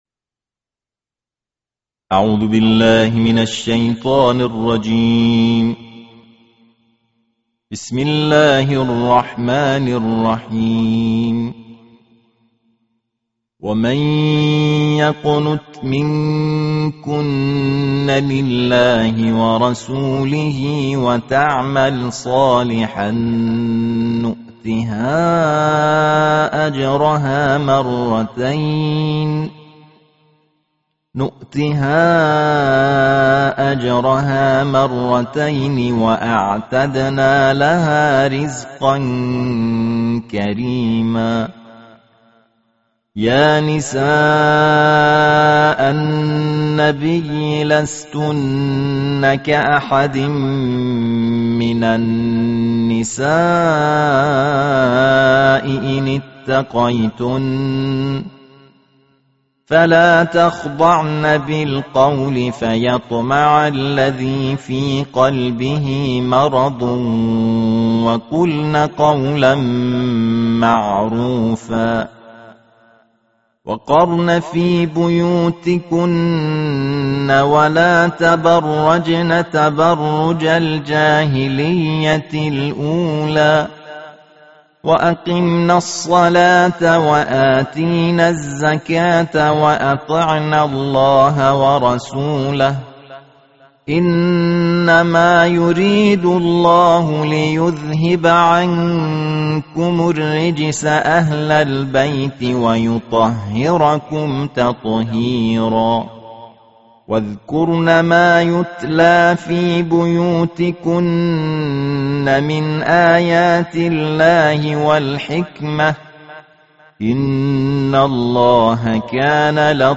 Tartil Juz Ke-22 Alquran
Qori Internasional